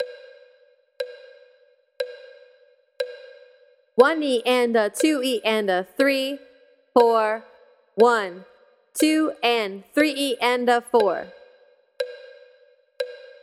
In this example the musician is counting out 16th notes using short syllables like “e” and “ah” for the 16th notes.